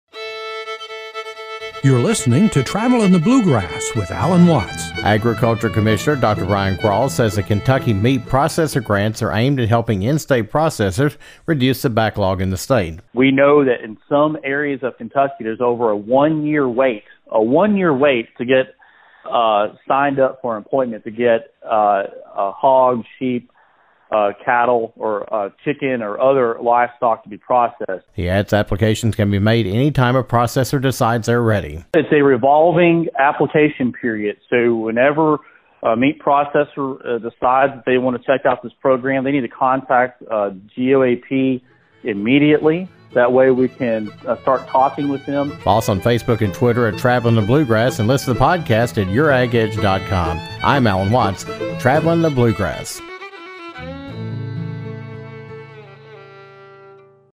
A second round of grant money is available to help Kentucky meat processors make expansions and improvements to handle increased demand. Kentucky Agriculture Commissioner Dr. Ryan Quarles talks about the latest round of funding through the Governor’s Office of Ag Policy, what the money can be used for, and how to apply.